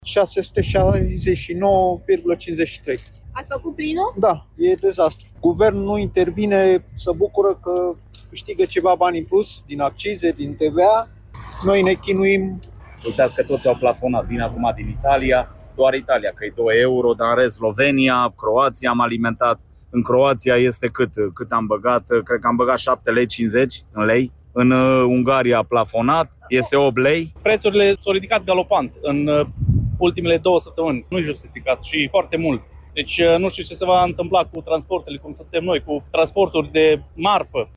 Guvernul se bucură că va câștiga ceva bani în plus, spune un bărbat
„Prețurile s-au ridicat galopant în ultimele două săptămâni, nu este justificat și este foarte mult. Nu știu ce se va întâmpla cu transporturile, cum suntem noi cu transporturile de marfă”, a declarat un alt bărbat.